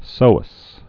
(sōəs)